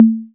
808-Tom2.wav